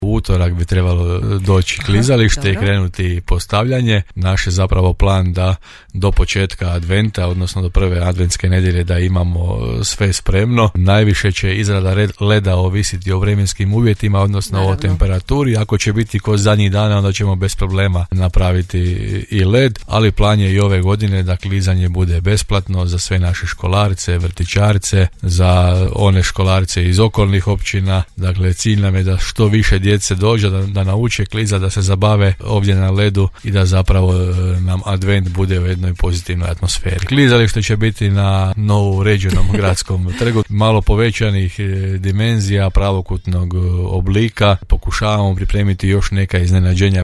– najavio je gradonačelnik Grada Hrvoje Janči u Gradskim temama Podravskog radija.